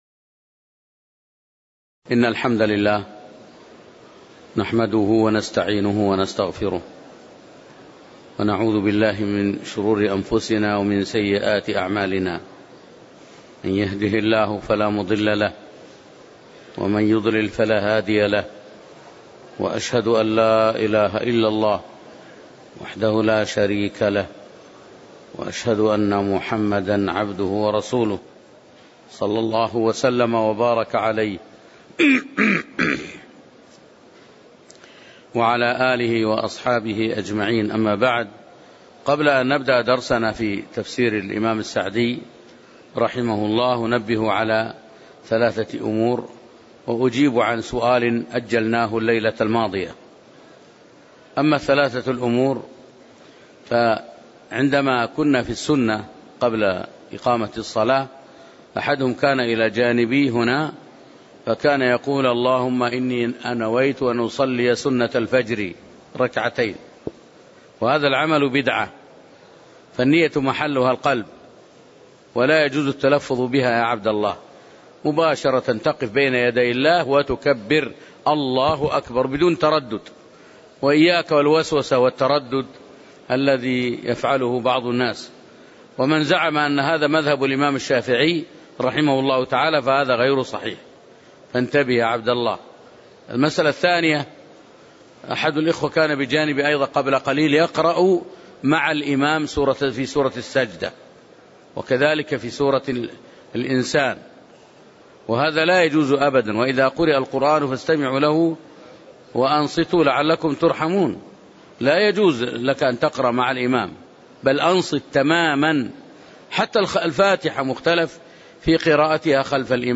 تاريخ النشر ٢٩ ربيع الثاني ١٤٣٨ هـ المكان: المسجد النبوي الشيخ